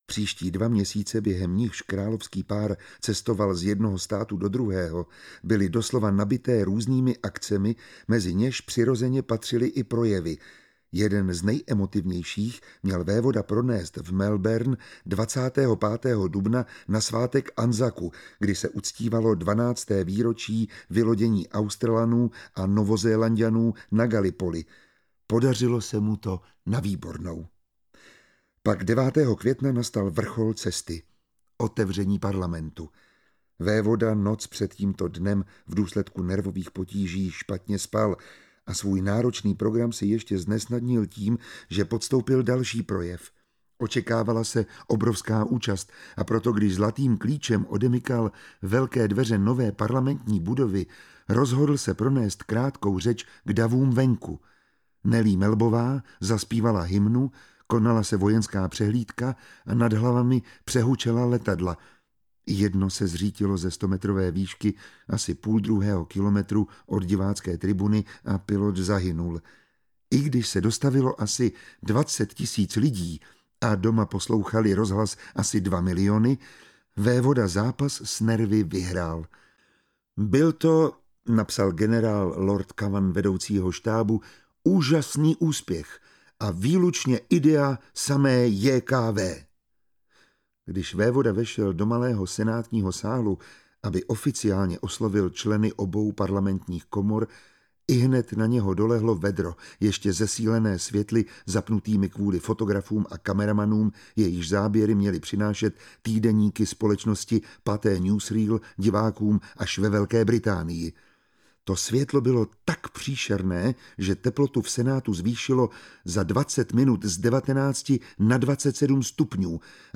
Králova řeč audiokniha
Čte Miroslav Táborský.
Ukázka z knihy
• InterpretMiroslav Táborský
kralova-rec-audiokniha